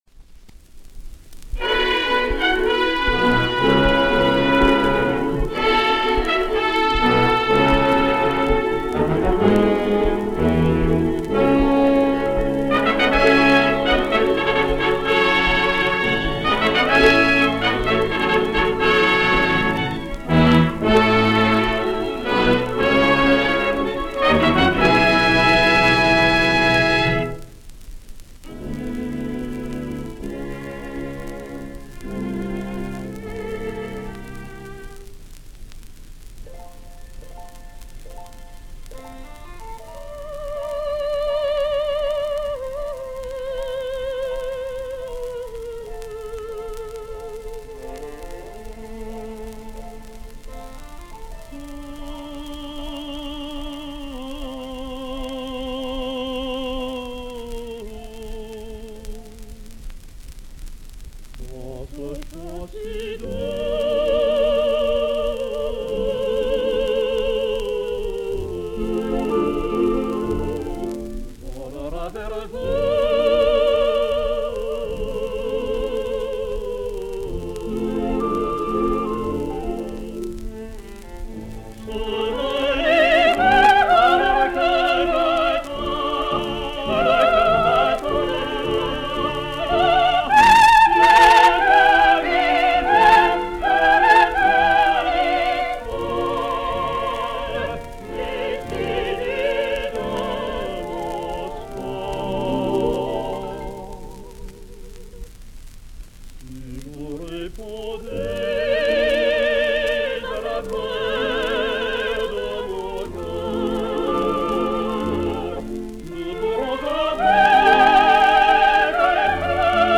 Medley
Pot-pourri